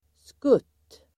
Uttal: [skut:]